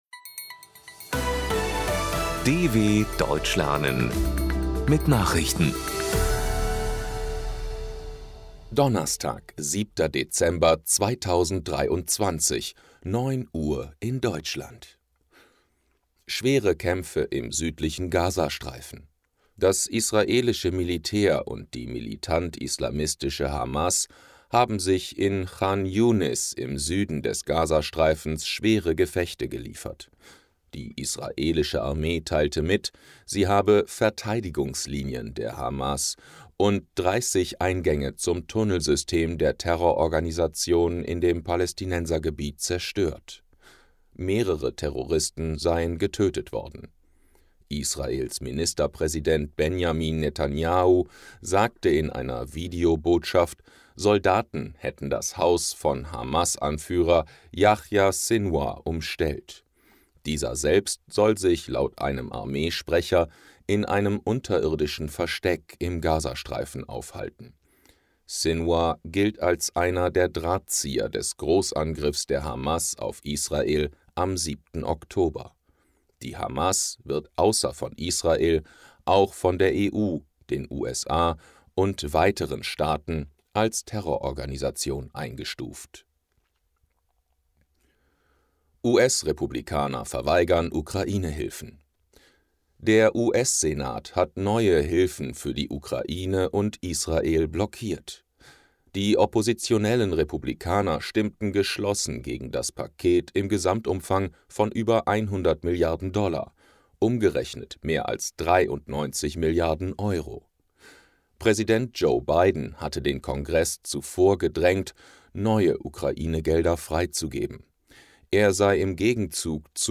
07.12.2023 – Langsam Gesprochene Nachrichten
Trainiere dein Hörverstehen mit den Nachrichten der Deutschen Welle von Donnerstag – als Text und als verständlich gesprochene Audio-Datei.